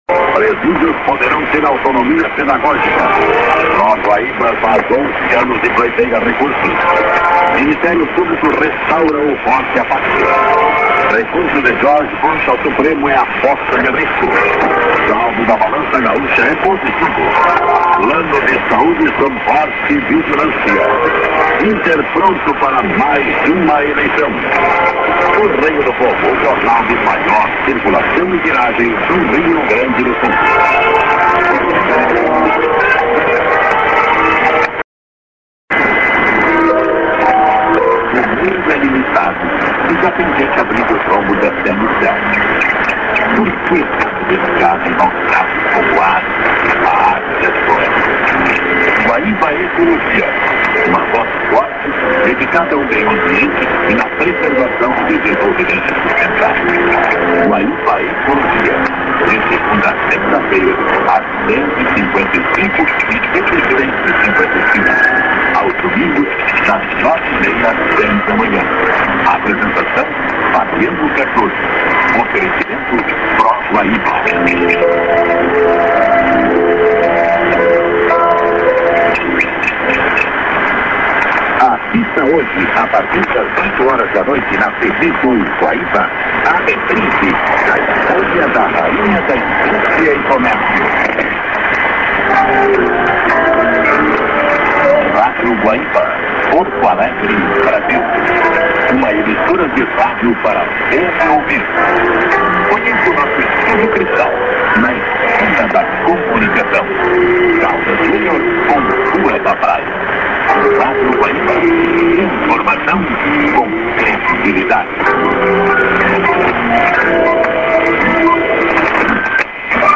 ->ID(man)->cut->01'33":ID(man)->TS->ID(man)->